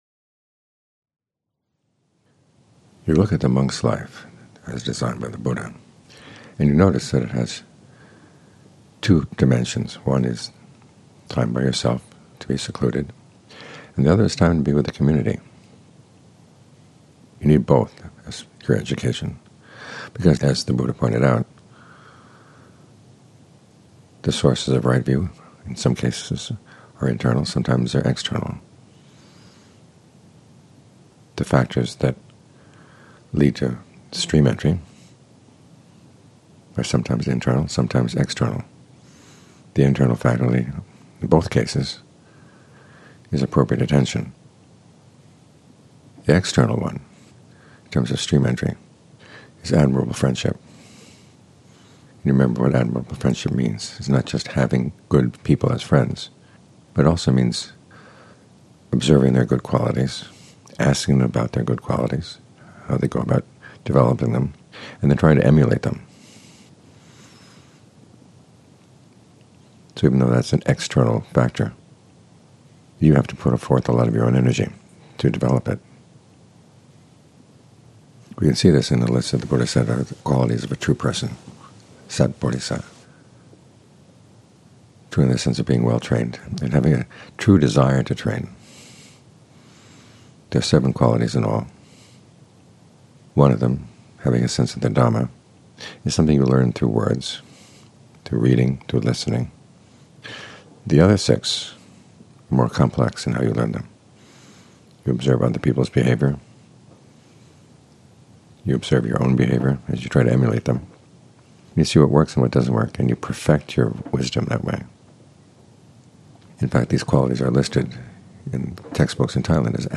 Evening Talks